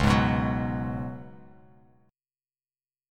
C#7sus4 chord